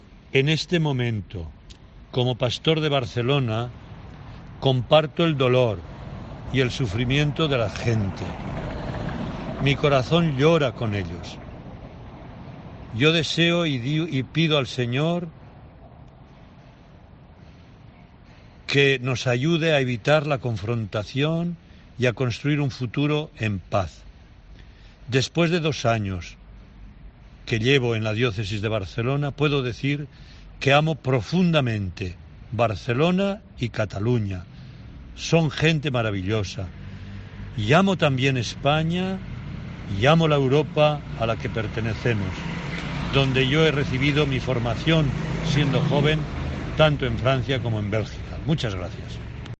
Monseñor Omella, en el curso de su ponencia esta tarde en el congreso Rethinking Europe organizado por la COMECE en Roma, ha señalado que pide al Señor que "os ayude a evitar la confrontación y a construir un futuro en paz".